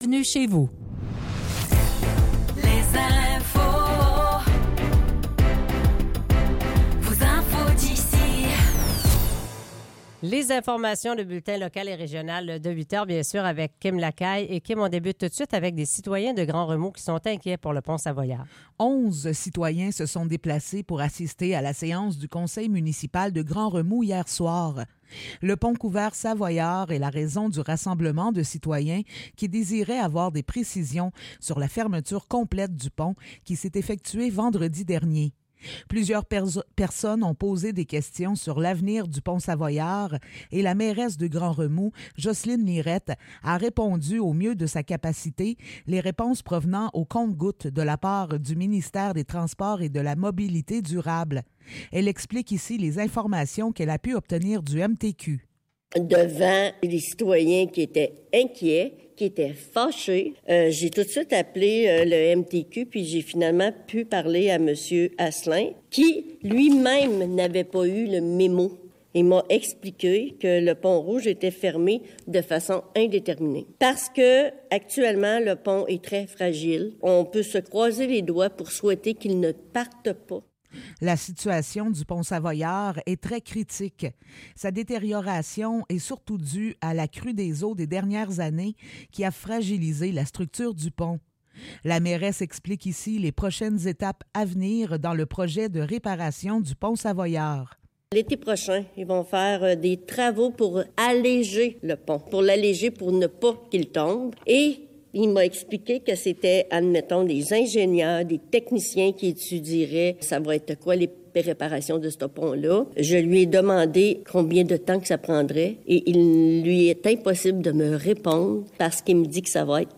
Nouvelles locales - 5 mars 2024 - 8 h